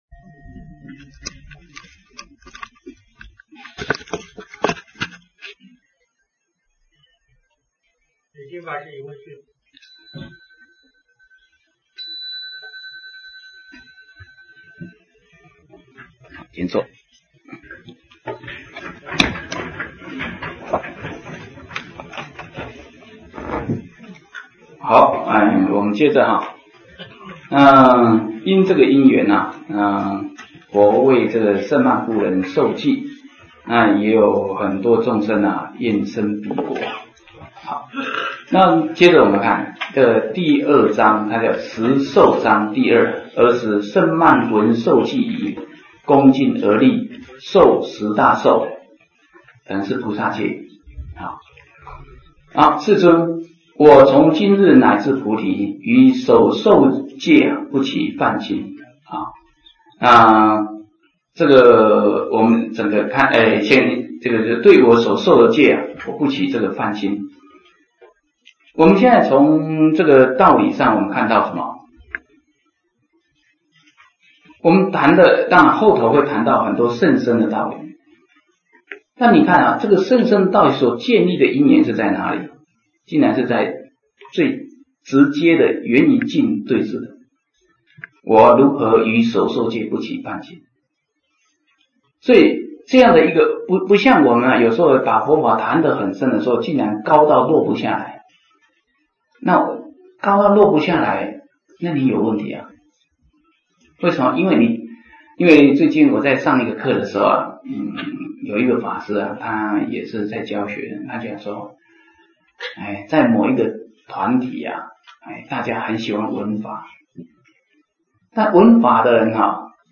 40胜鬘经(闽南佛学院)